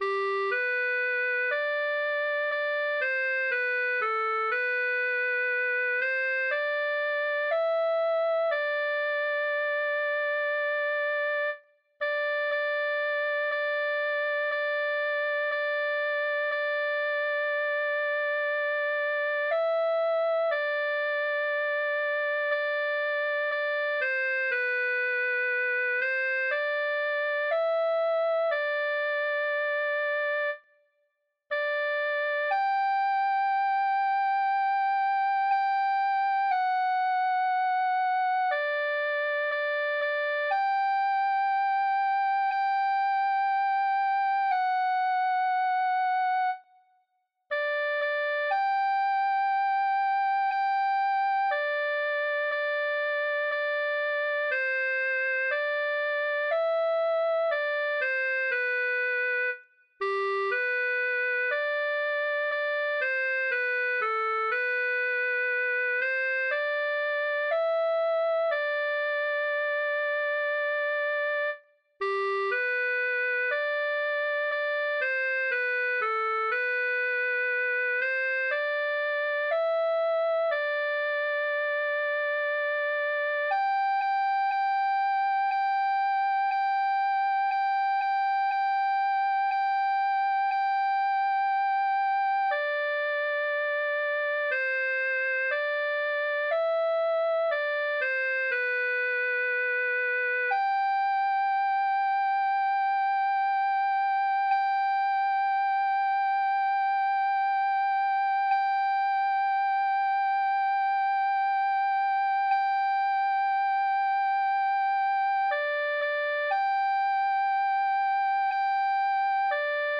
Voci (mp3): solo tenor,